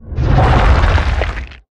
Sfx_creature_shadowleviathan_swimgrowl_os_04.ogg